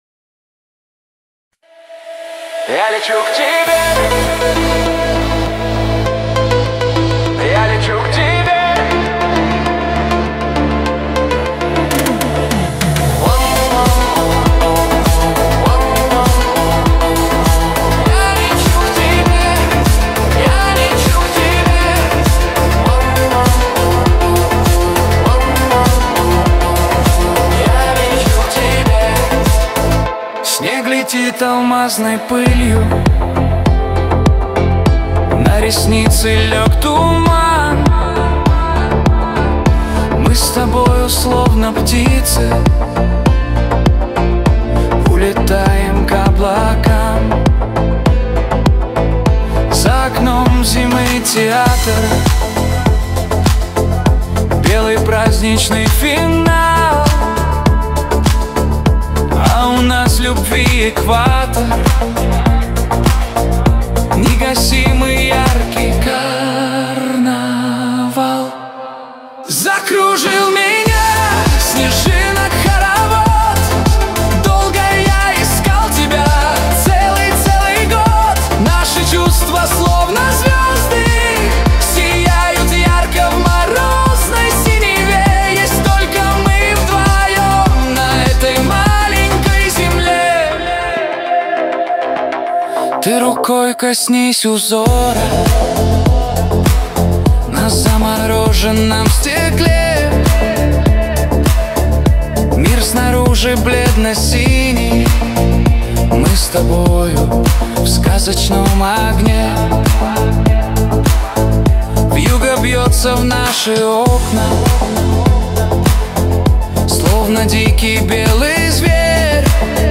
Лучшая Новогодняя песня о любви